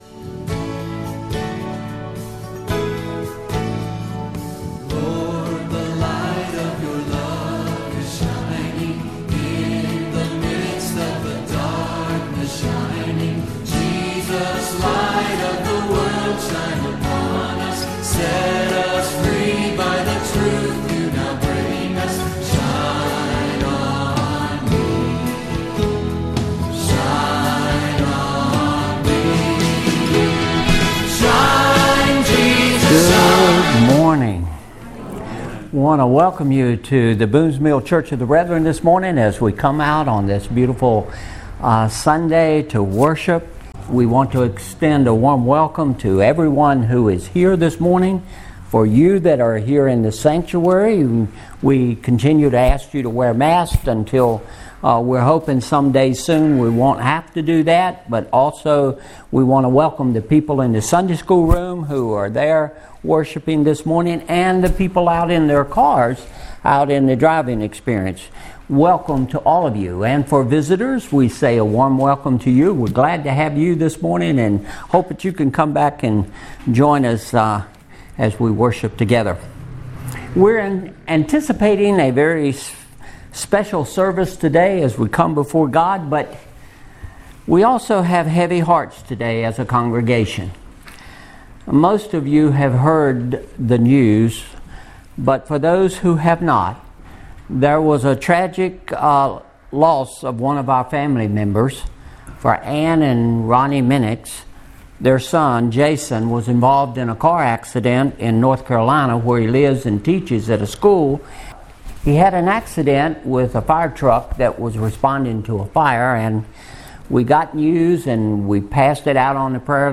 Apr 18, 2021 How to be Wise with Your Money MP3 Notes Discussion Sermons in this Series Sermon Series How to Face Our Future!